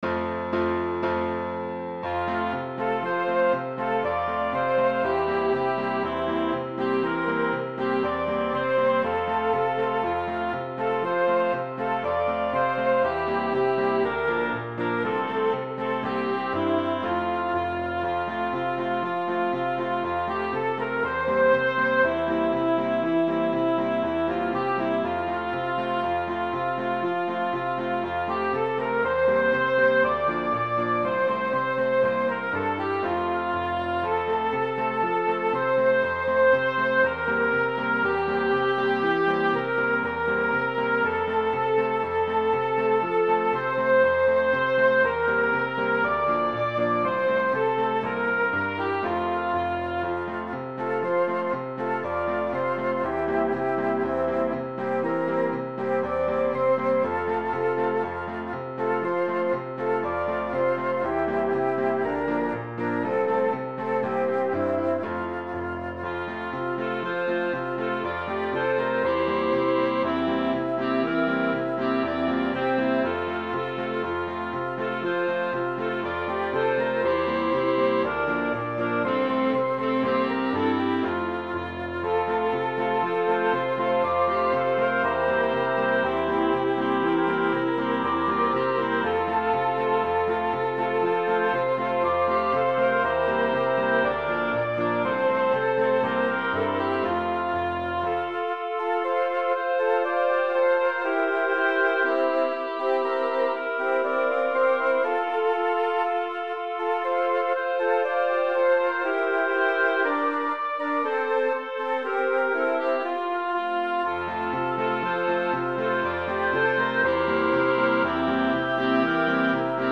2. Logo iníciase o canon dende a primeira voz.
4. Volve soar o piano na derradeira volta.
5. Finalízase a peza coas codas finais.